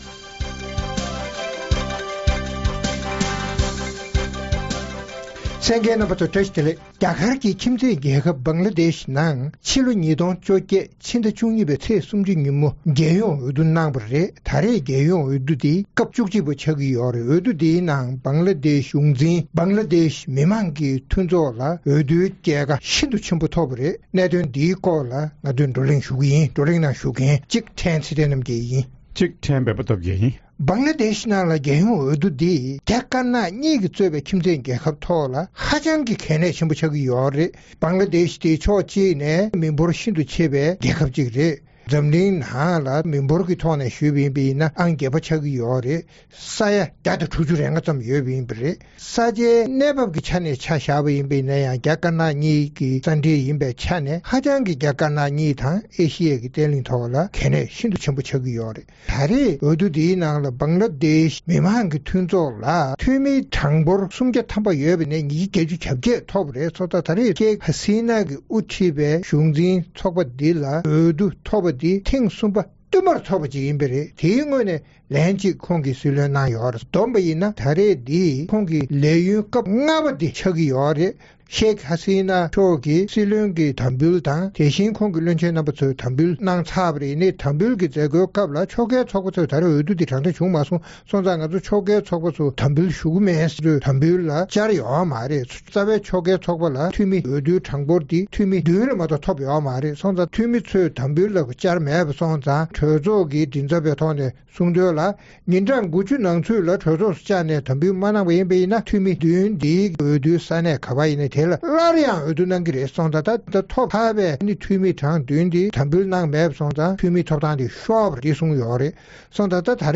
རྩོམ་སྒྲིག་པའི་གླེང་སྟེགས་ཞེས་པའི་ལེ་ཚན་ནང་། རྒྱ་གར་གྱི་ཁྱིམ་མཚེས་རྒྱལ་ཁབ་ Bangladesh ནང་རྒྱལ་ཡོངས་འོས་བསྡུ་གནང་སྟེ་རྒྱལ་ཡོངས་འོས་བསྡུའི་རྒྱལ་ཁ་Sheikh Hasina ཡིས་དབུ་འཁྲིད་པའི་ Bangladesh མི་མང་ཚོགས་པར་འཐོབ་ཡོད་ཅིང་། རྒྱལ་ཡོངས་འོས་བསྡུའི་སྐོར་དང་། འོས་བསྡུའི་གྲུབ་འབྲས་ཀྱིས་ཁྱིམ་མཚེས་རྒྱལ་ཁབ་རྒྱ་དཀར་ནག་གཉིས་ལ་ཤུགས་རྐྱེན་ཇི་ཐེབས་སོགས་ཀྱི་སྐོར་རྩོམ་སྒྲིག་འགན་འཛིན་རྣམ་པས་བགྲོ་གླེང་གནང་བ་གསན་རོགས་གནང་།